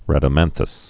(rădə-mănthəs)